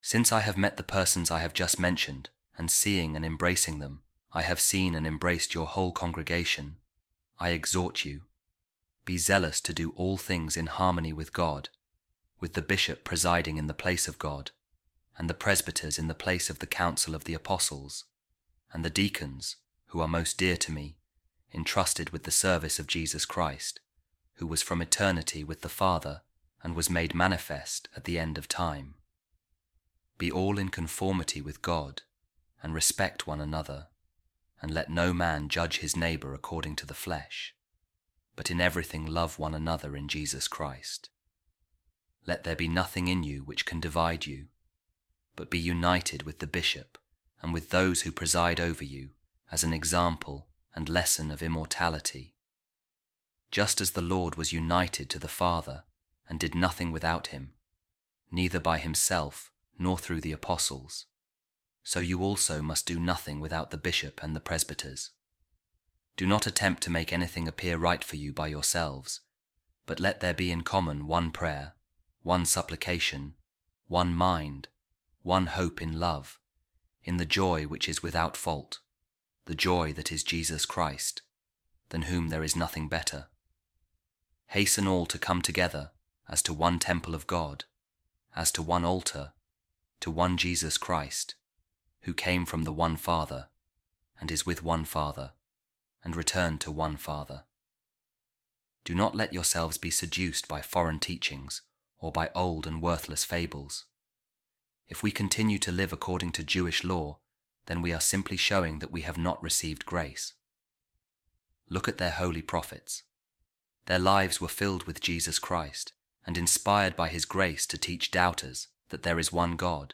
Divine Office | Office Of Readings